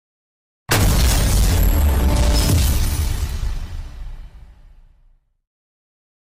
Fortnite Victory Royale Sound Effect sound effects free download
Fortnite Victory Royale Sound Effect (DISTORTION)